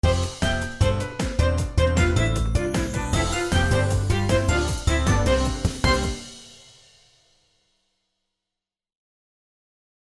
ジングル